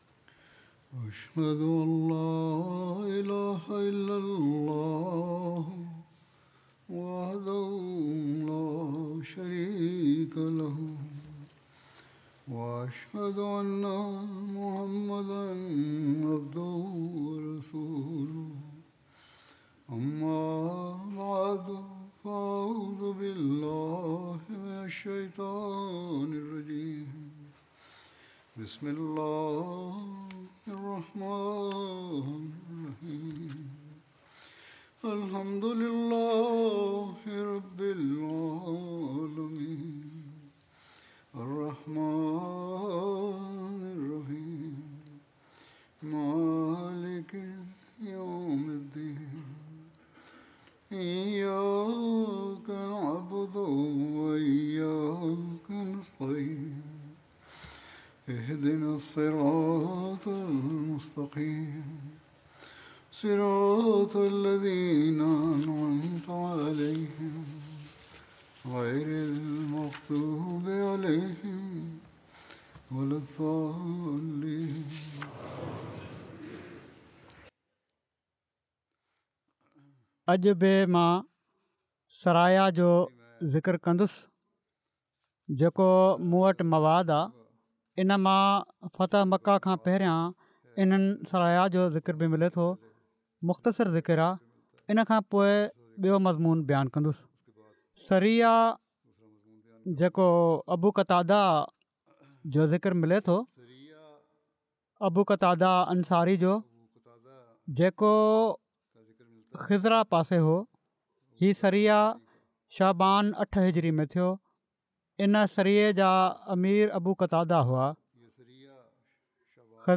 Sindhi Friday Sermon by Head of Ahmadiyya Muslim Community
Sindhi Translation of Friday Sermon delivered by Khalifatul Masih